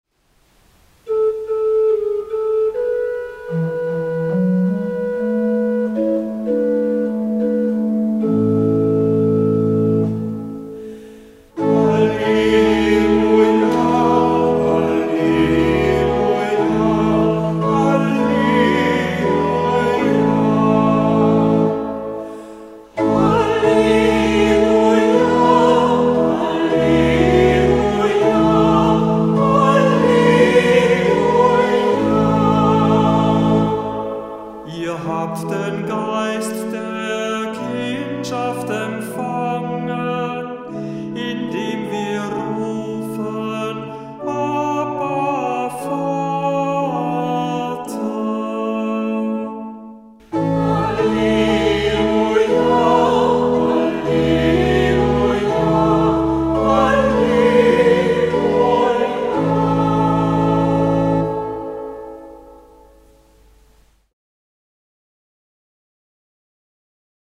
Hörbeispiele aus dem Halleluja-Büchlein